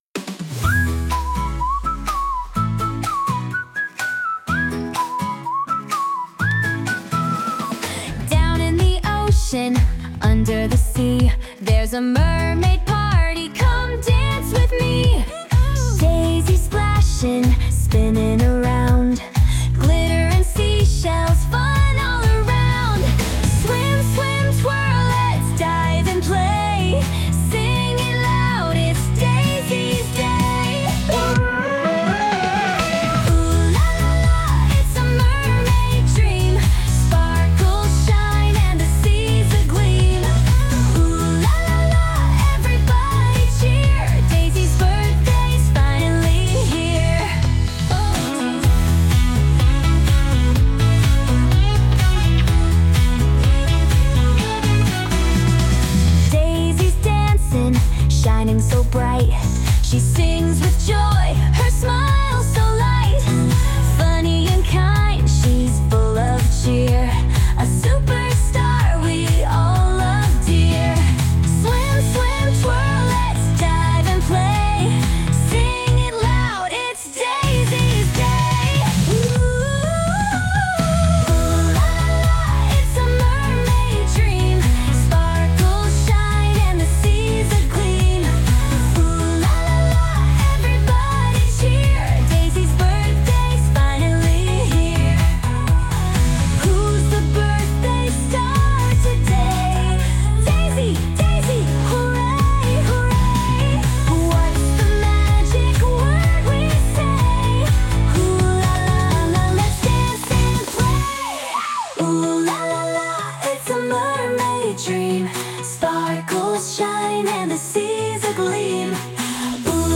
a mermaid party anthem full of glitter